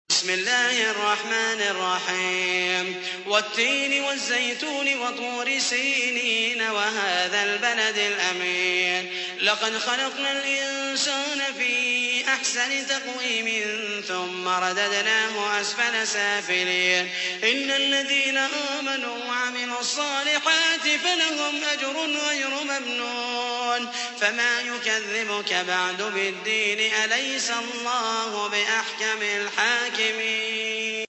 تحميل : 95. سورة التين / القارئ محمد المحيسني / القرآن الكريم / موقع يا حسين